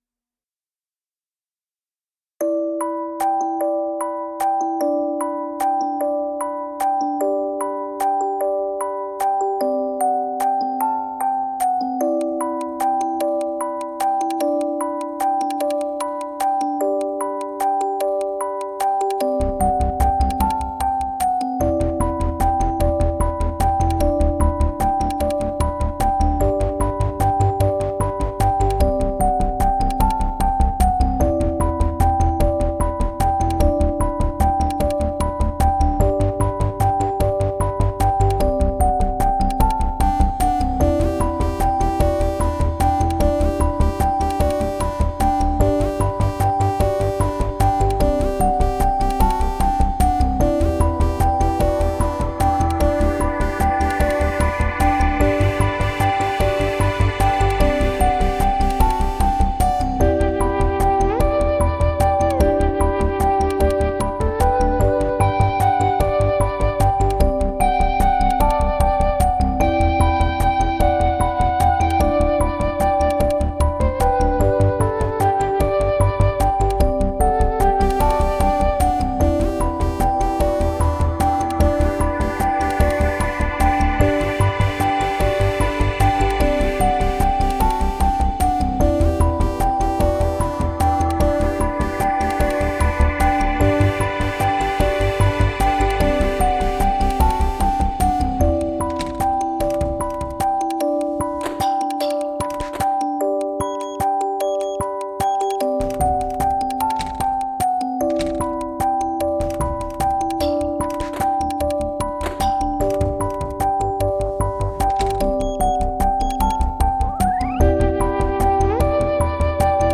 Category: Ambient